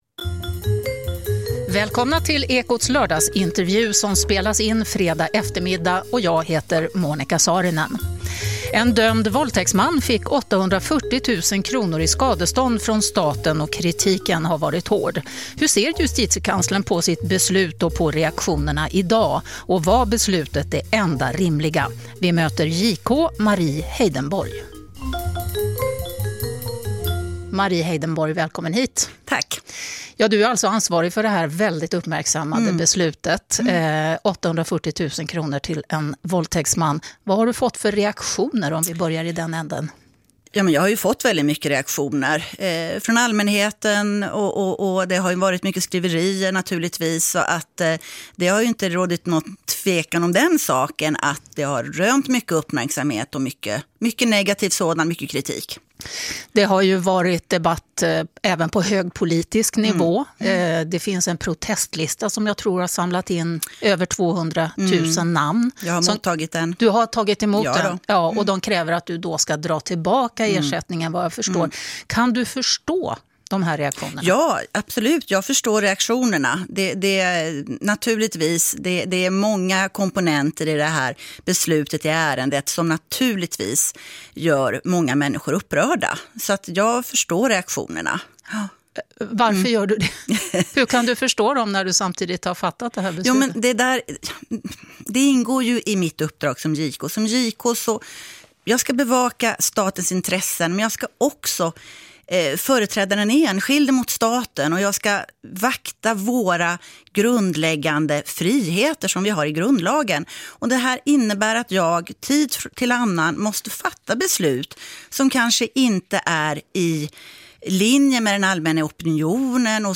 INRIKES. EU och inte minst Sveriges statsminister Stefan Löfven har kritiserat Polen för att man där inte värnar domstolarnas oberoende, men i Ekots lördagsintervju säger Justitiekanslerna Mari Heidenborg att även det svenska systemet borde ses över.
Ekots-lordagsintervju-med-JK-Mari-Heidenborg.mp3